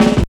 44 SNARE 2-L.wav